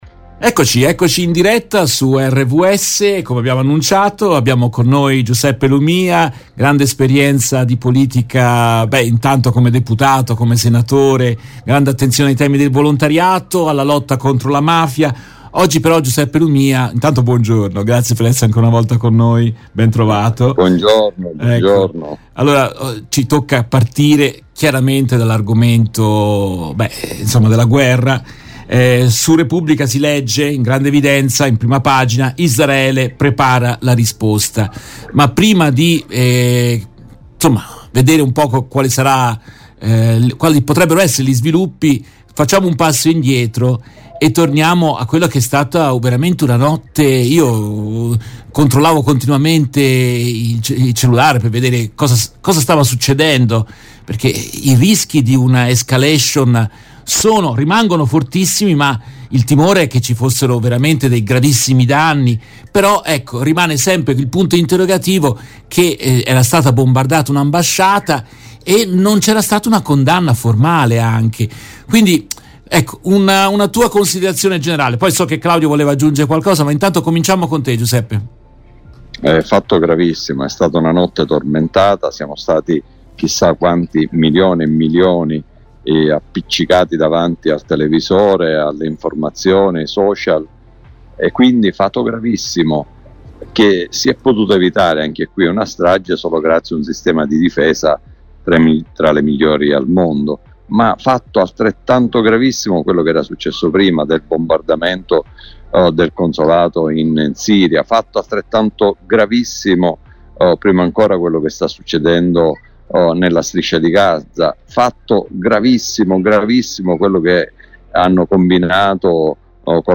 Nel corso della trasmissione in diretta del 15 aprile 2024
intervista per RVS Giuseppe Lumia, già Deputato e Senatore della Repubblica.